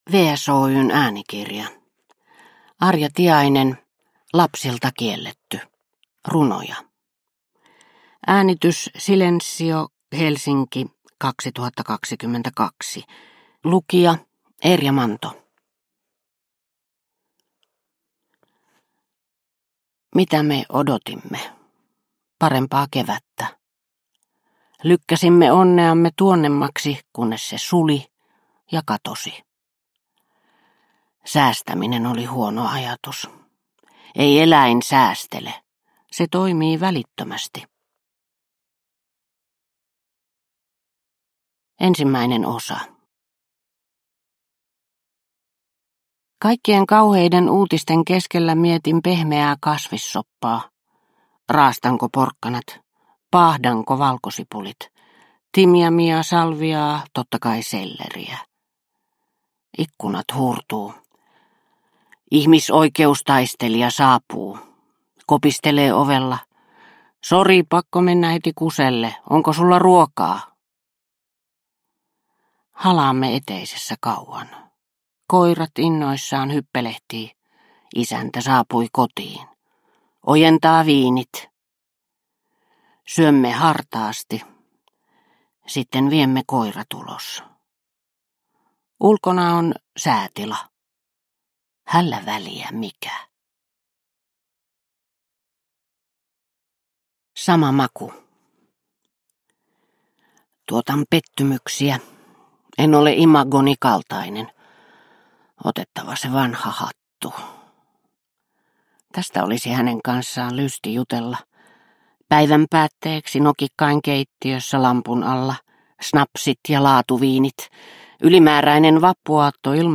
Lapsilta kielletty – Ljudbok – Laddas ner